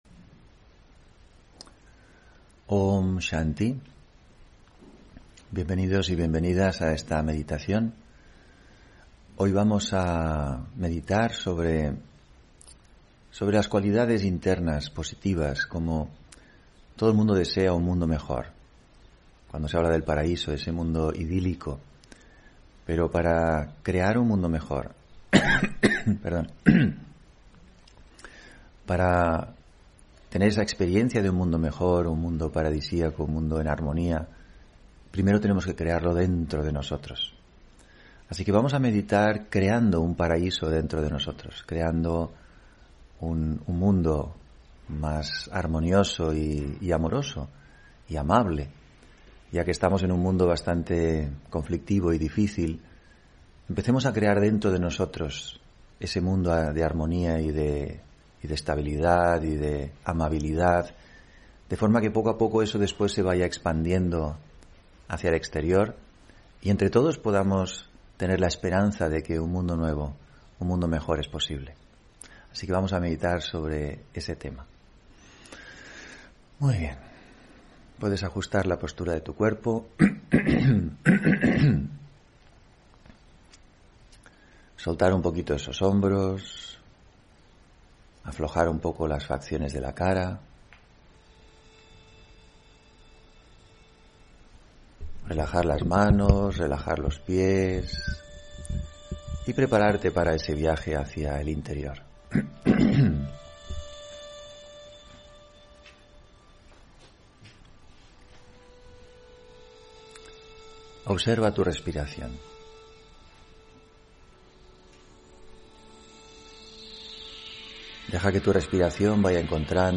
Meditación de la mañana: El discernimiento, la clave del éxito